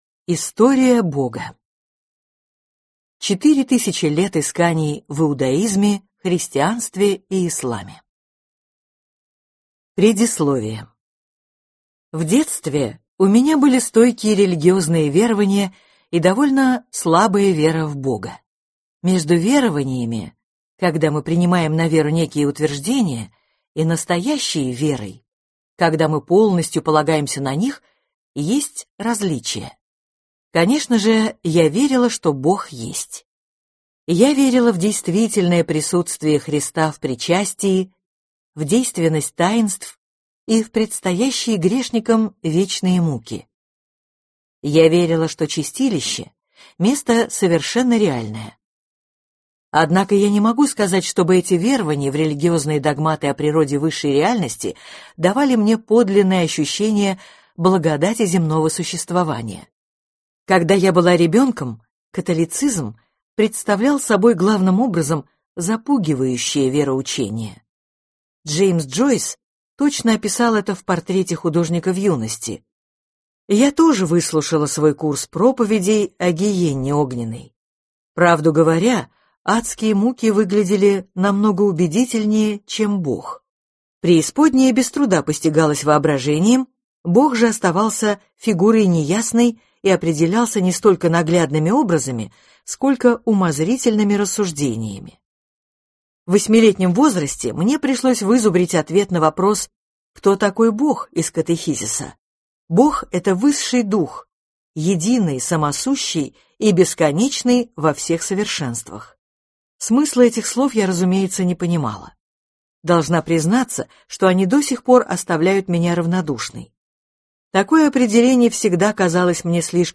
Аудиокнига История Бога: 4000 лет исканий в иудаизме, христианстве и исламе | Библиотека аудиокниг